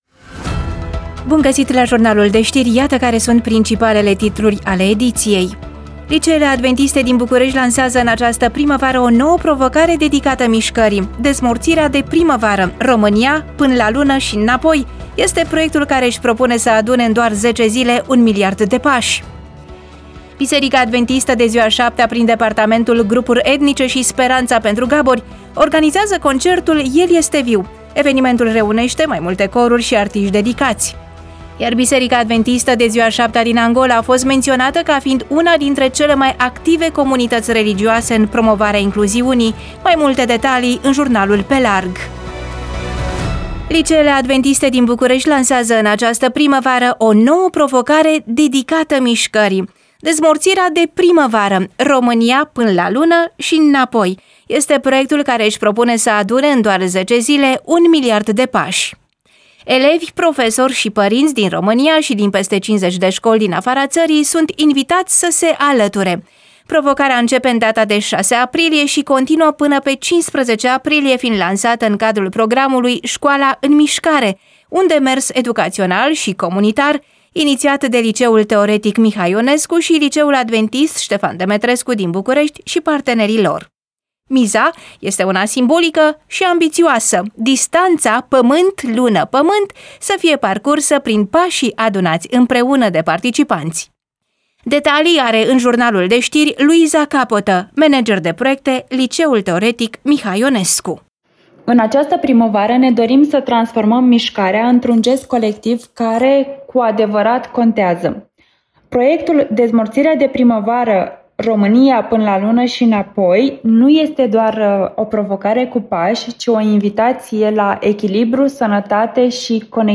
EMISIUNEA: Știri Radio Vocea Speranței